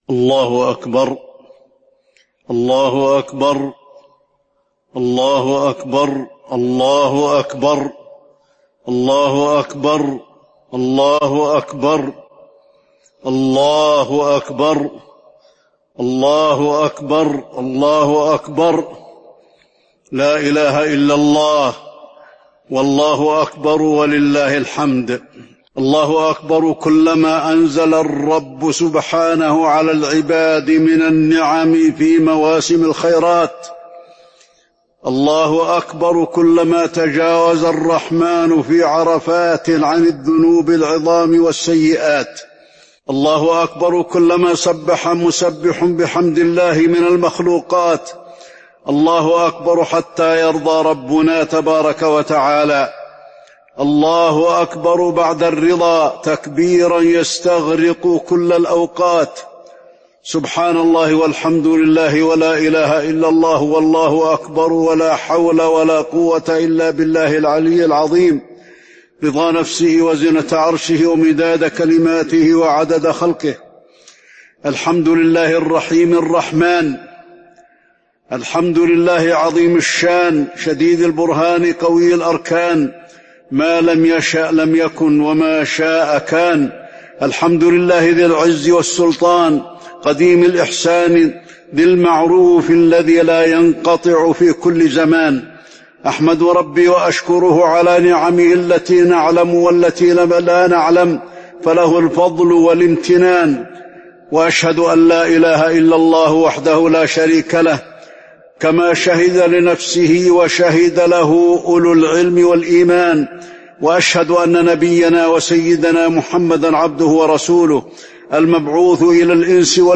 خطبة عيد الأضحى - المدينة - الشيخ علي الحذيفي - الموقع الرسمي لرئاسة الشؤون الدينية بالمسجد النبوي والمسجد الحرام
خطبة عيد الأضحى - المدينة - الشيخ علي الحذيفي
المكان: المسجد النبوي